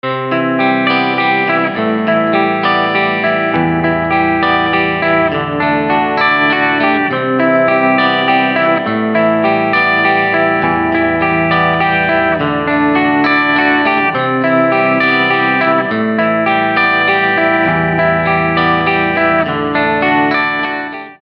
GR5 Preset – RUN (Clean)
run-clean.mp3